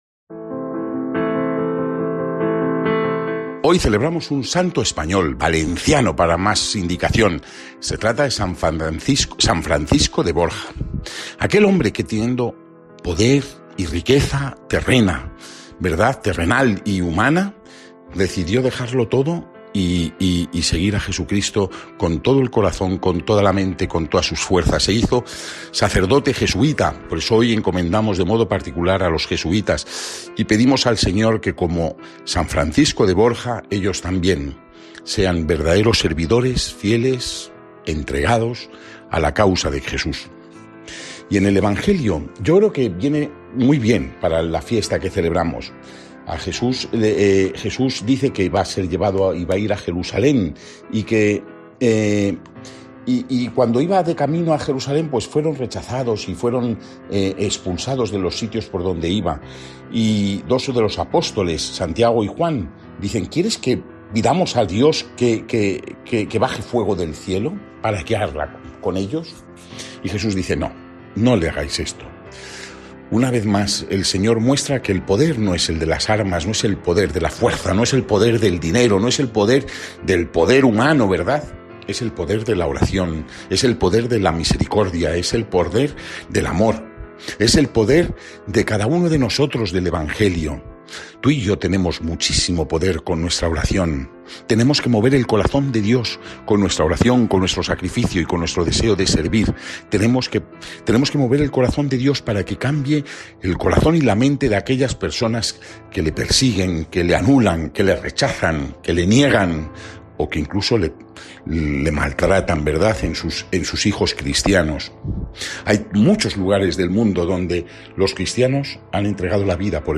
Evangelio según san Lucas (9,51-56) y comentario